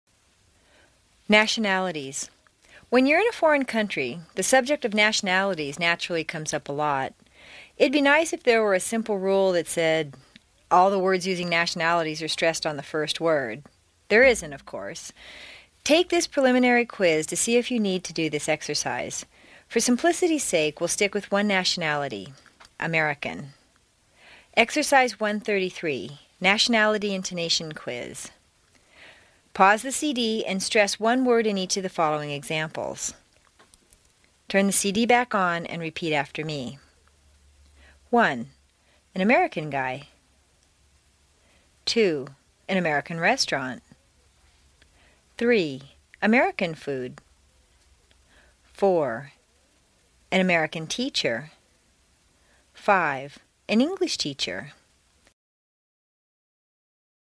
美式英语正音训练第27期:Exercise 1-33 Nationality Intonatio 听力文件下载—在线英语听力室
在线英语听力室美式英语正音训练第27期:Exercise 1-33 Nationality Intonatio的听力文件下载,详细解析美式语音语调，讲解美式发音的阶梯性语调训练方法，全方位了解美式发音的技巧与方法，练就一口纯正的美式发音！